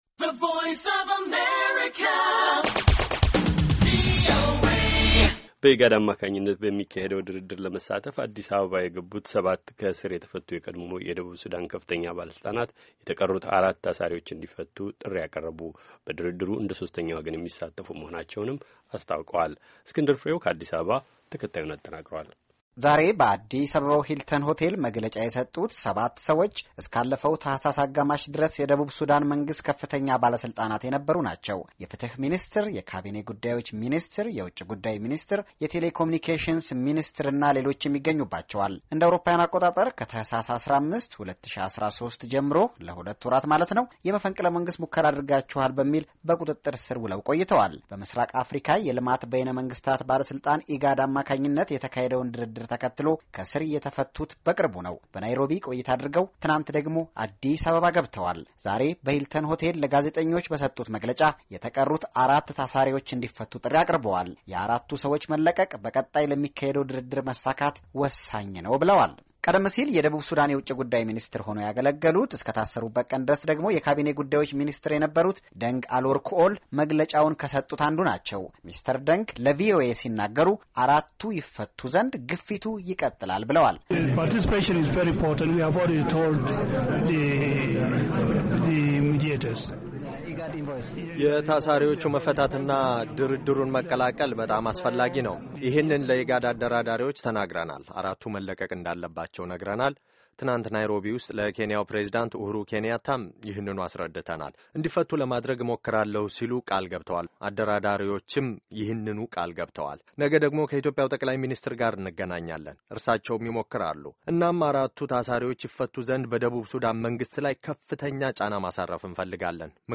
South Sudan former officials press briefing in Addis